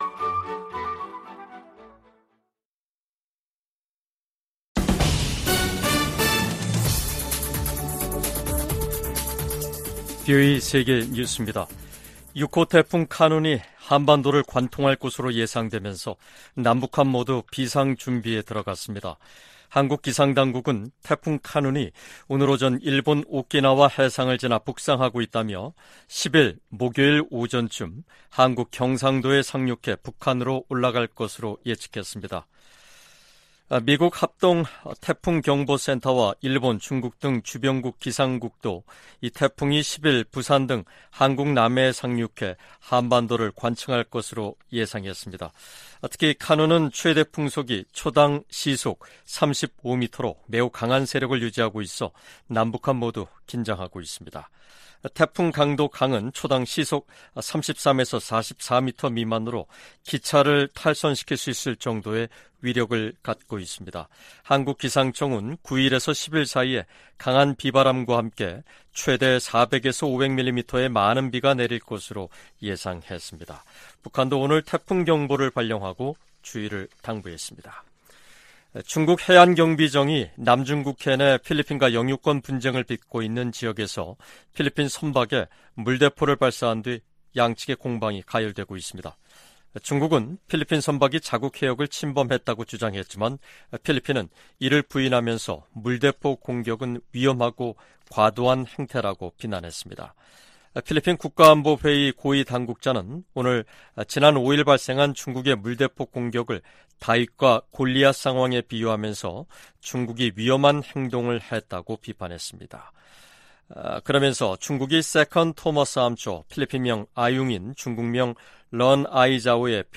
VOA 한국어 간판 뉴스 프로그램 '뉴스 투데이', 2023년 8월 7일 3부 방송입니다. 유엔 제재 대상 북한 유조선이 중국 해역에서 발견된 가운데 국무부는 모든 제재 위반을 심각하게 받아들인다고 밝혔습니다. 미국 민주당 상원의원들이 바이든 행정부에 서한을 보내 북한의 암호화폐 탈취에 대응하기 위한 계획을 공개할 것을 요구했습니다.